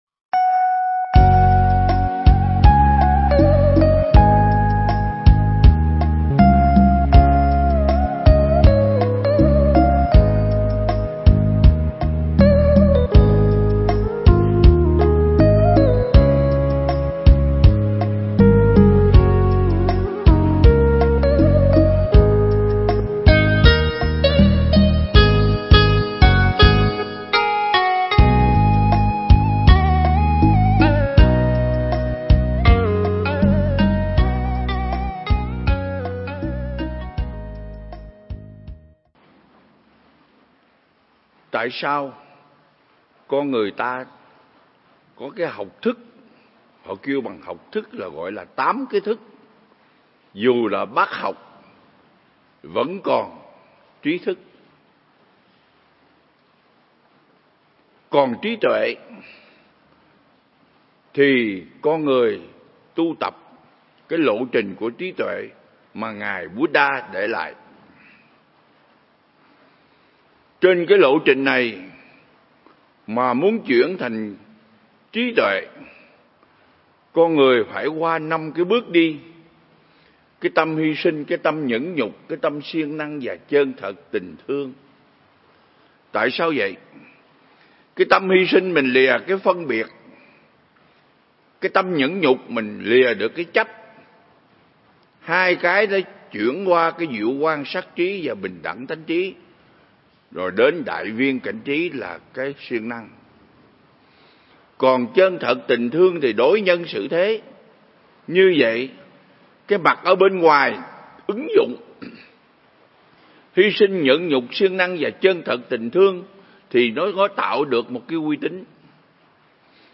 giảng tại Viện Nghiên Cứu Và Ứng Dụng Buddha Yoga Việt Nam ( Đà Lạt)